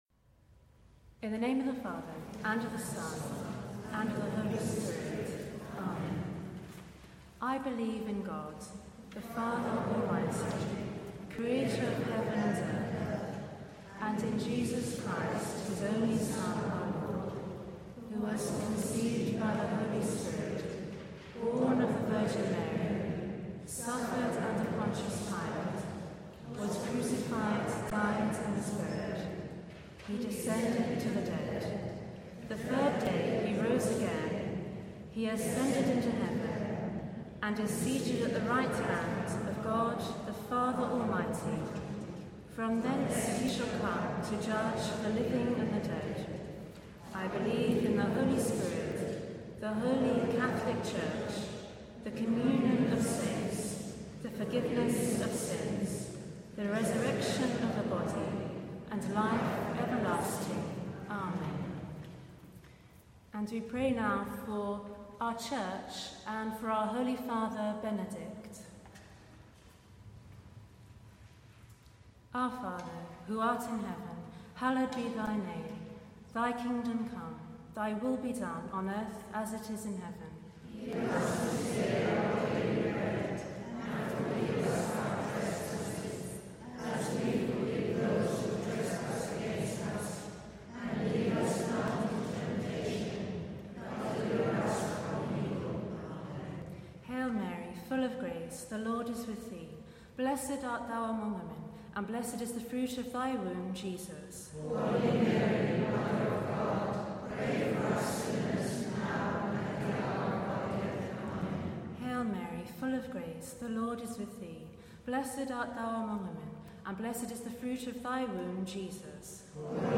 The Rosary with Westminster Cathedral Rosary Group - Totus2us
Prayers before the Glorious Mysteries